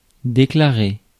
Ääntäminen
France (Paris): IPA: [de.kla.ʁe]